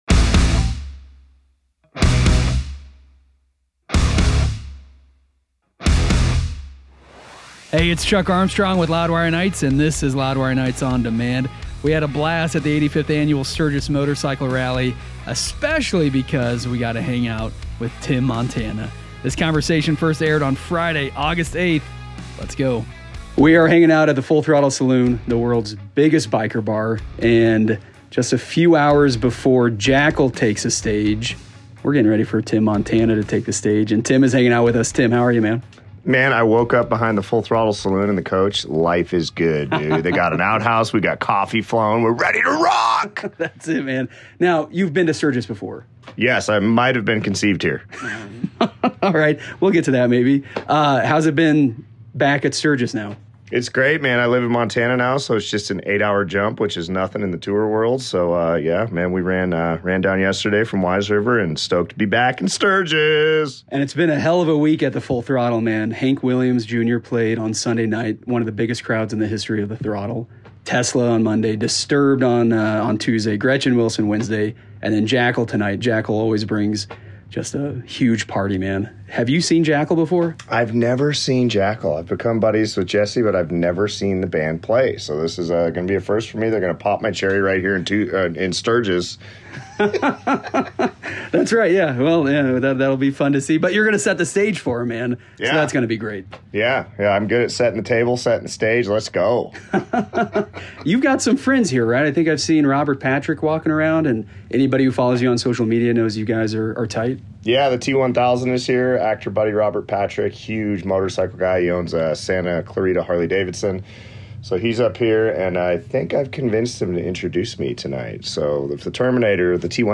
In addition to playing the best music in rock, the show features the latest rock news and interviews with artists. Those interviews are featured on Loudwire Nights: On Demand.